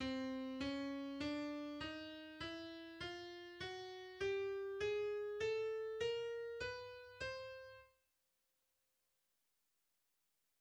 A lilypond a zongora hangszínét használja alaphelyzetben, de ez is változtatható.